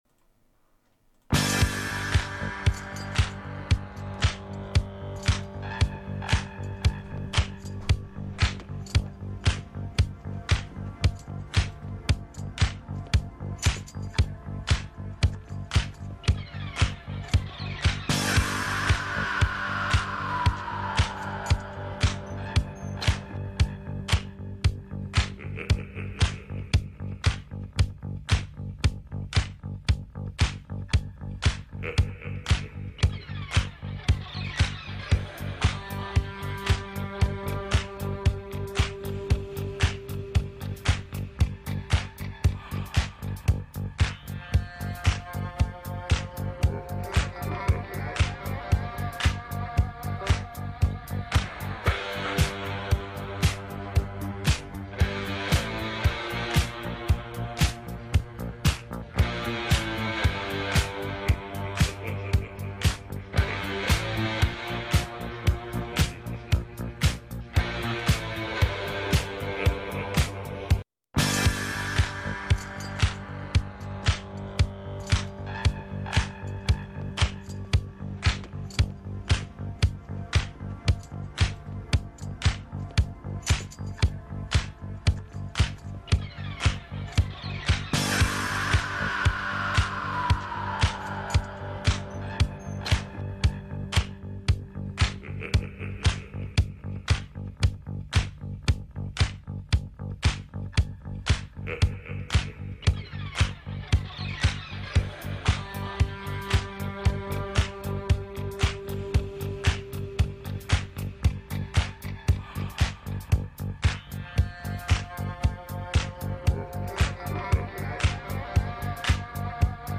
"C'est de la Locale" est une émission quotidienne diffusée en direct de 18 à 19h du lundi au vendredi.